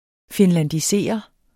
Udtale [ fenlandiˈseˀʌ ]